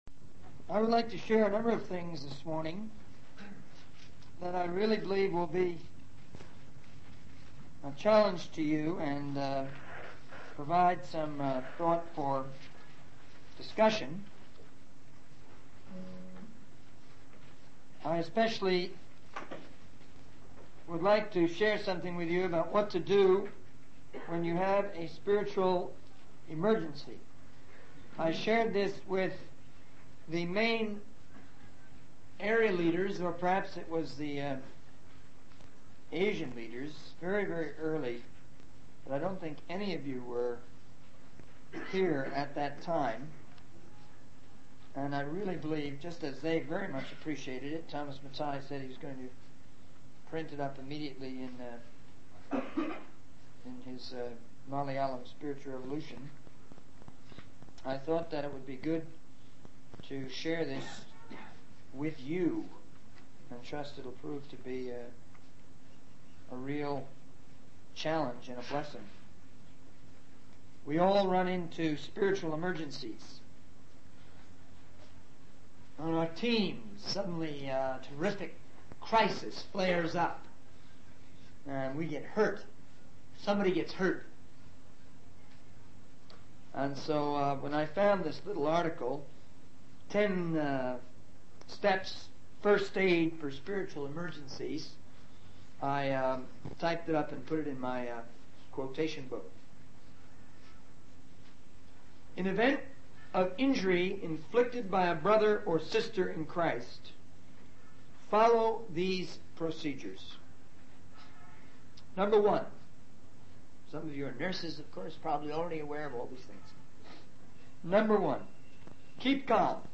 In this sermon, the speaker addresses the issue of pride and the difficulty people have in receiving.